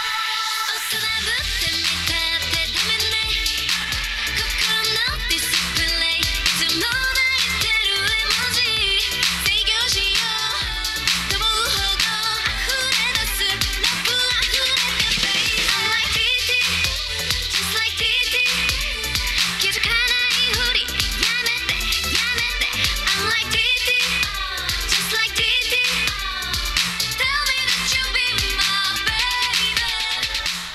以下は２０時間のエージング後のハイレゾ録音です。